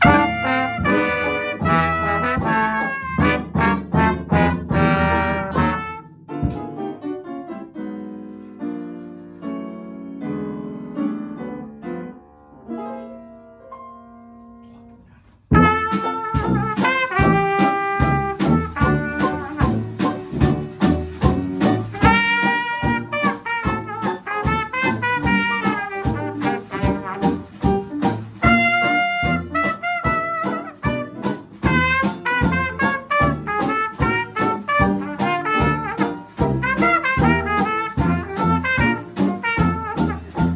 trompette
clarinette
trombone
piano, vocal
banjo
symphony bass
batterie.